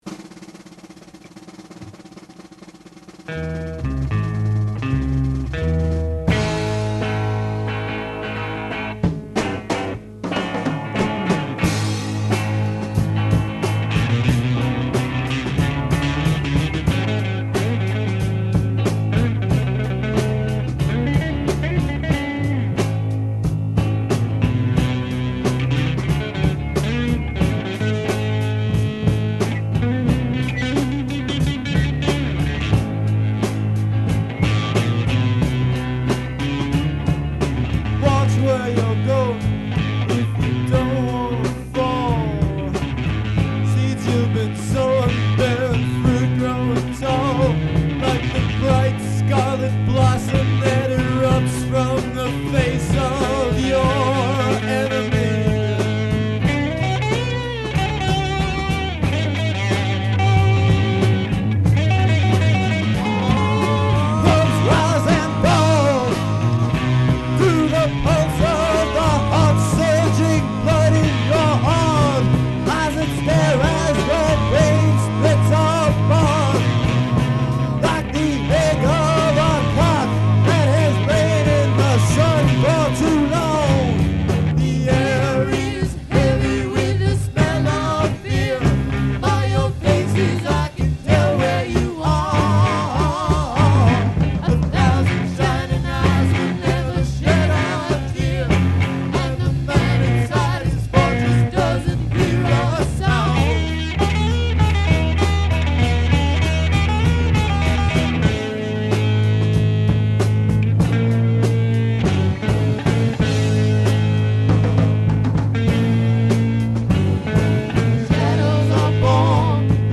live at Westboro High School, Westboro, NH